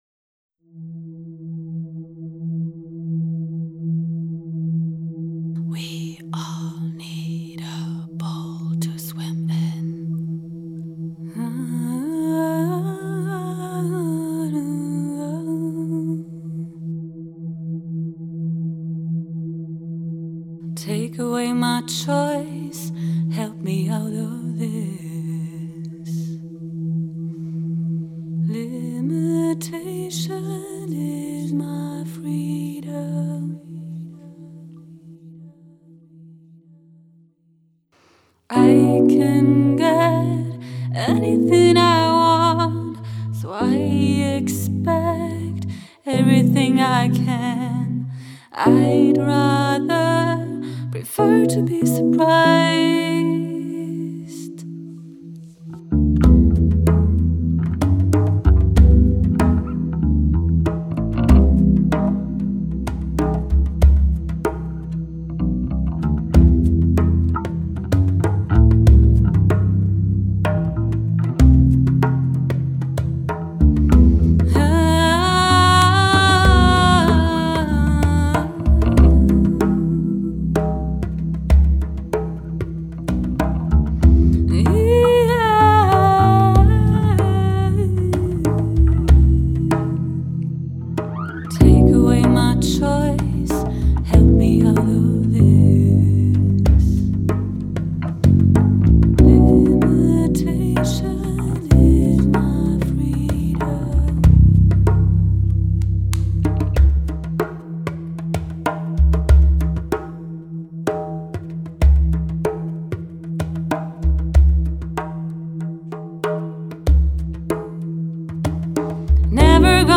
The songs were written and recorded under a tight time schedule, so these are just workshop recordings.